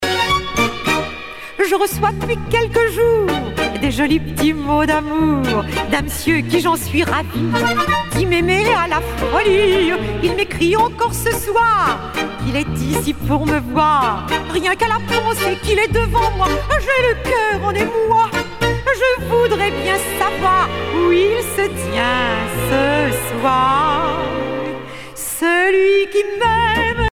strophique
Pièce musicale éditée